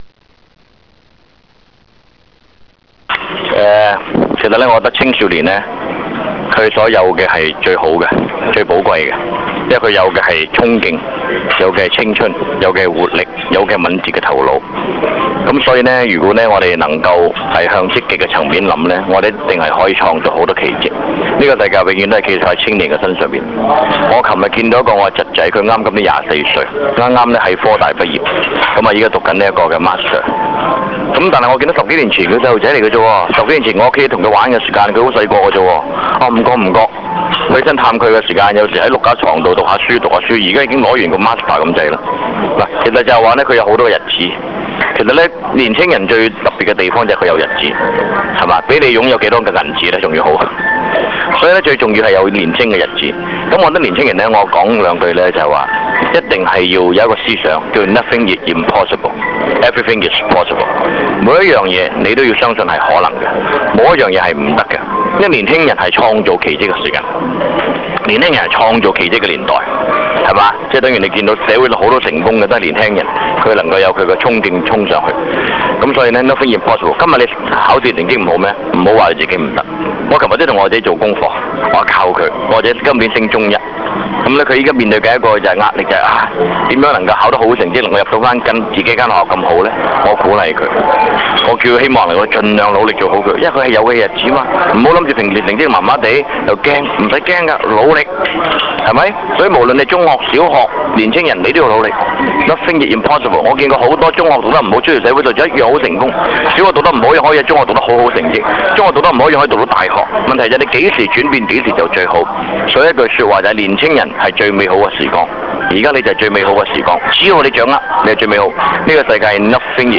由青少年暑期活動員會及傑出青年協會合辦的「飛躍人生」講座經已於十一月二十日完滿結束。
當晚香港大球場體育大樓的賽馬會演講廳座無虛設，參加者多數是青少年。